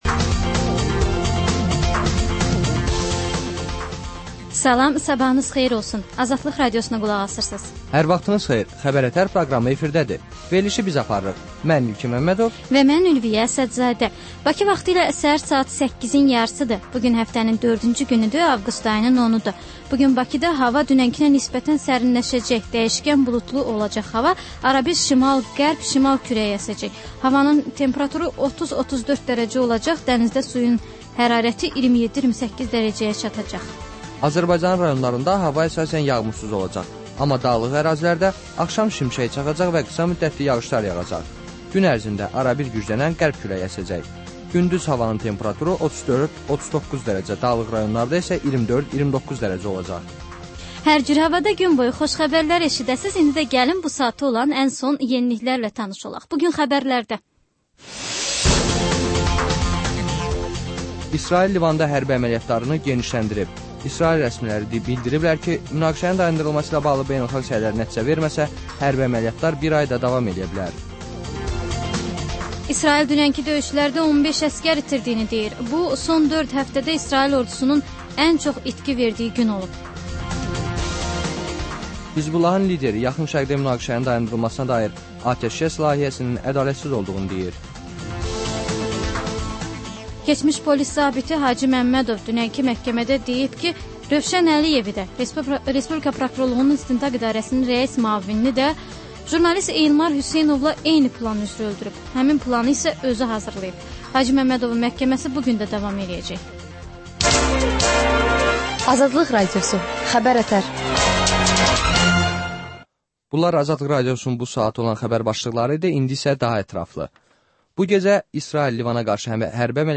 Səhər-səhər, Xəbər-ətərş Xəbərlər, reportajlar, müsahibələr